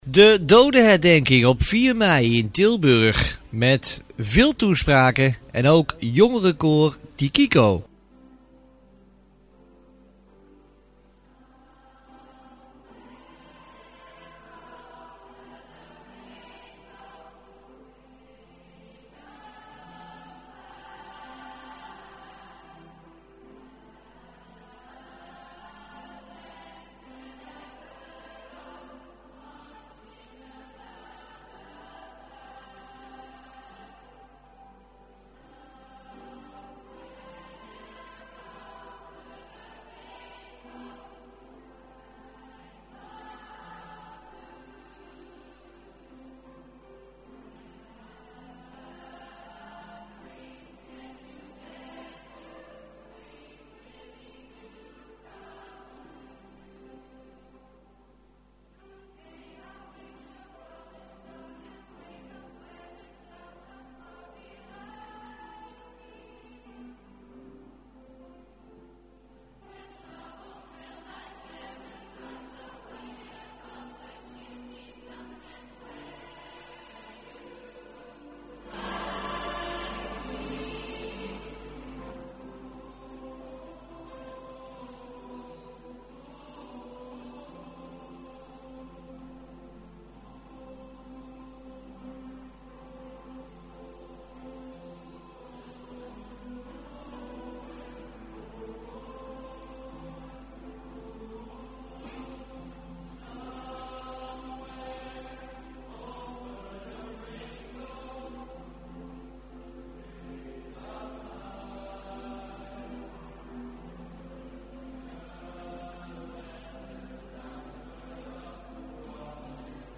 4 mei dodenherdenking in Tilburg Jongerenkoor Tikiko 1